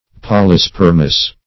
Search Result for " polyspermous" : The Collaborative International Dictionary of English v.0.48: Polyspermous \Pol`y*sper"mous\, a. [Gr.